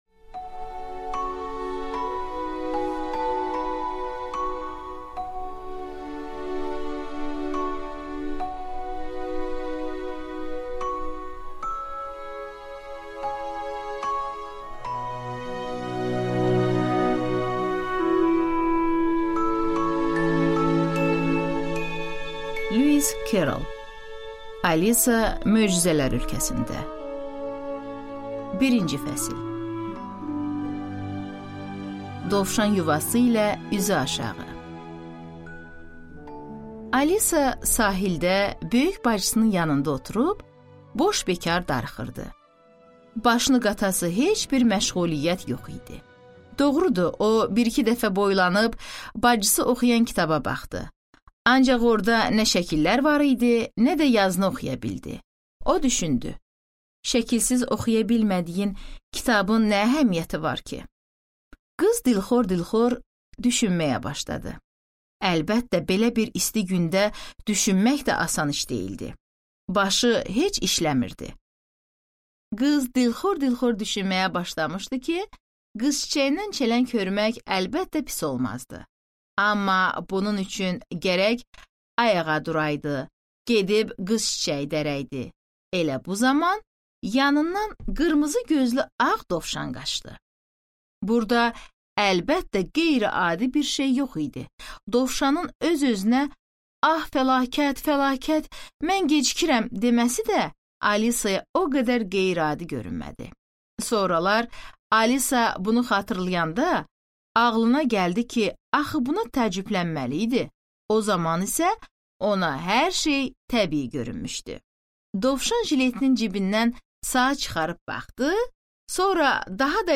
Аудиокнига Alisa möcüzələr ölkəsində | Библиотека аудиокниг